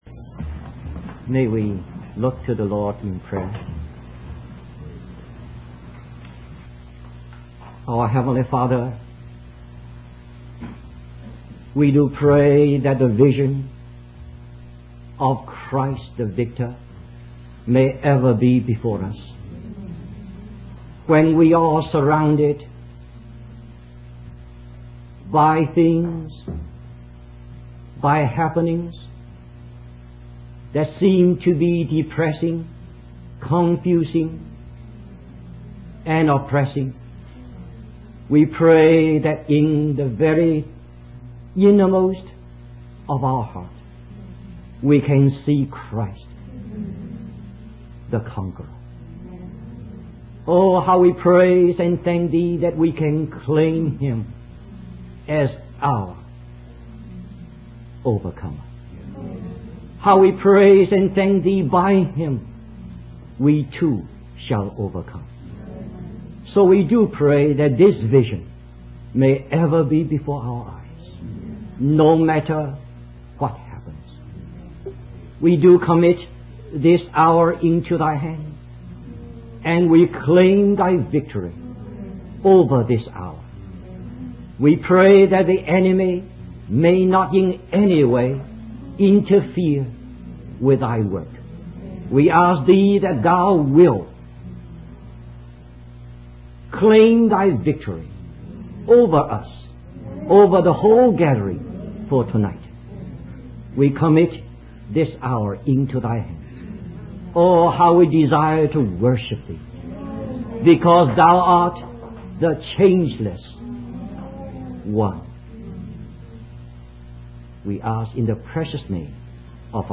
In this sermon, the speaker discusses various scenes from the book of Revelation. The first scene described is the vision of Christ as the Lion of the tribe of Judah and the Lamb standing before the throne. The second scene is the vision of Christ as the Son of Man walking among the seven golden candlesticks, symbolizing His presence in His own church.